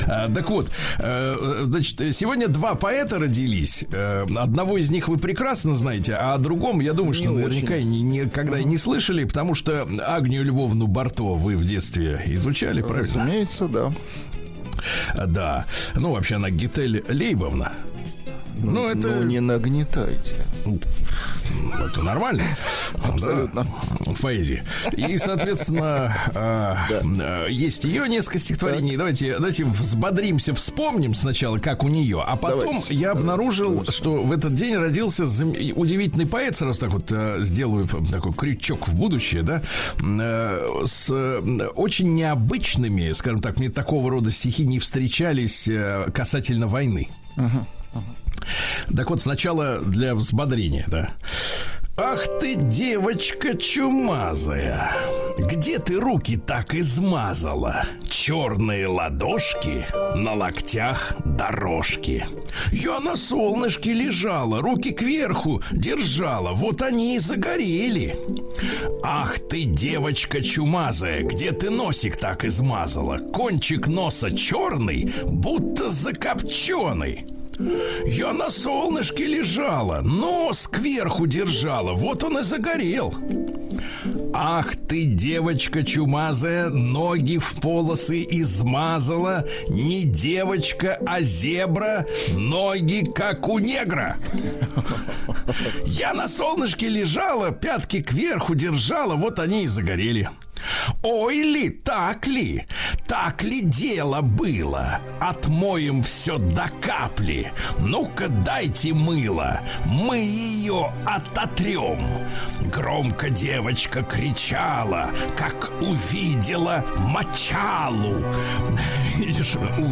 Дмитрий Кедрин (стихи).